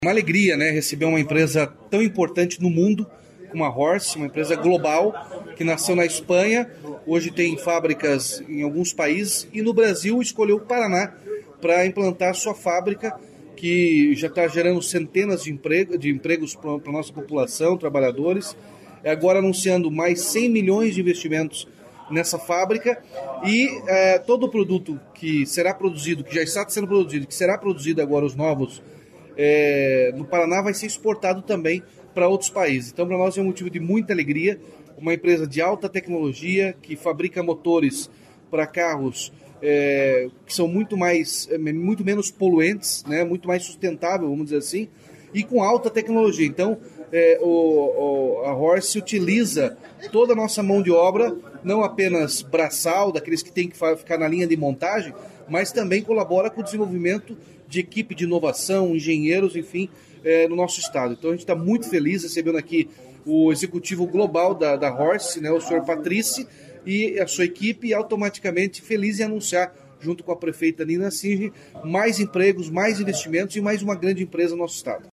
Sonora do governador Ratinho Junior sobre o investimento de R$ 100 milhões da Horse na produção de motores em São José dos Pinhais